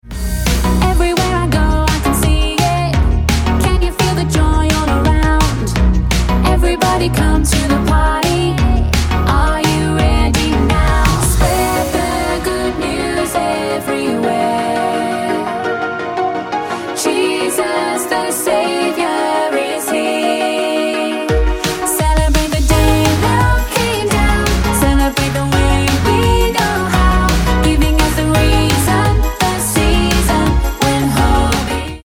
F#